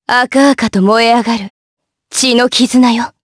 Lewsia_B-Vox_Skill3_jp.wav